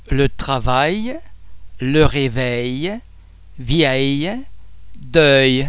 Please be mindful of the fact that all the French sounds are produced with greater facial, throat and other phonatory muscle tension than any English sound.
The French [ yeah ] sound is very much like the /y/ sound in the English words yes or eye.
·il
ille_letravail.mp3